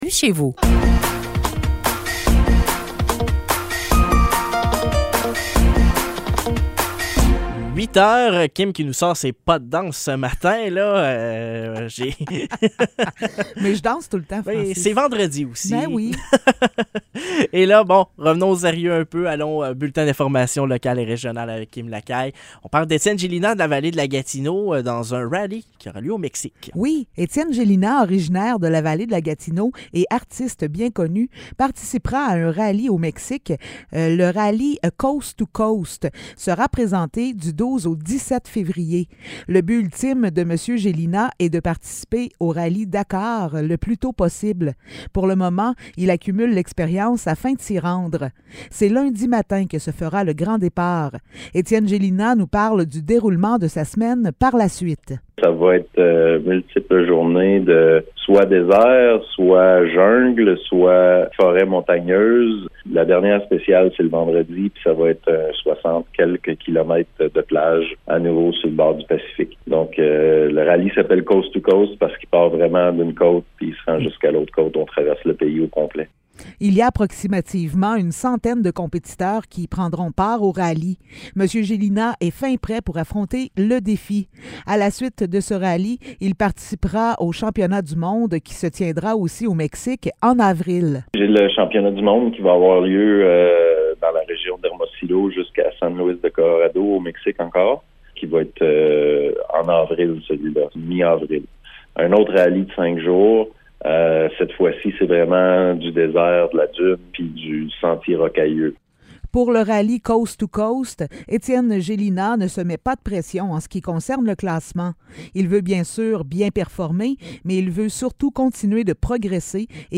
Nouvelles locales - 10 février 2023 - 8 h